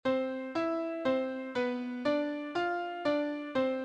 All the notes in a melody are taken to be the same length (here, a quarter-note) and each is represented by a number locating its pitch-class representative in the middle octave.
Pitch class sequence C E C B D F D C.